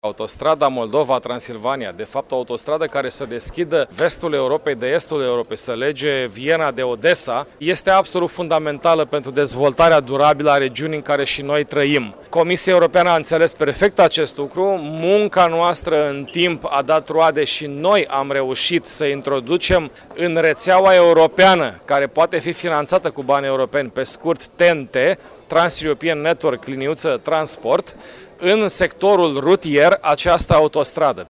Afirmaţia a fost făcută, astăzi, de preşedintele Consiliului Judeţean Iaşi, Cristian Adomniţei, la deschiderea seminarului cu tema „Interconectarea regiunilor Europei din perspectiva teritorială”.